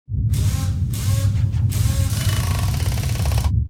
Repair4.wav